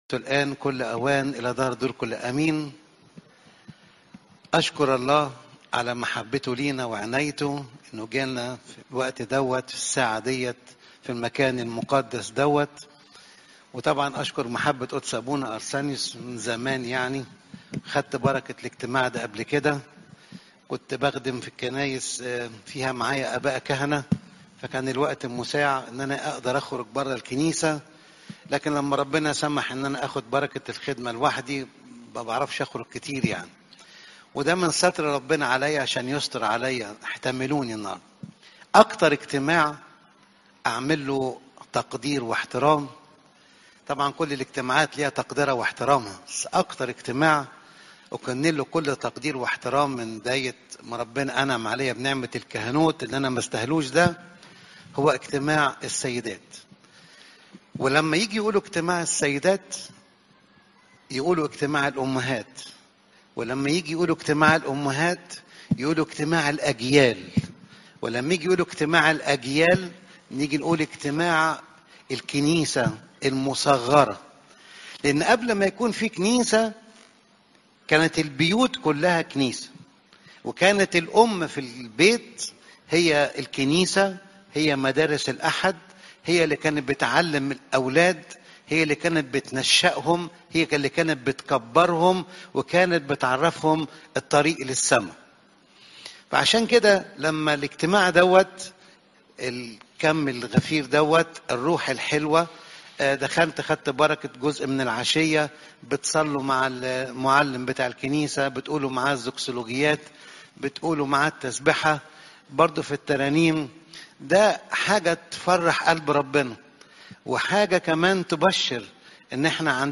إجتماع السيدات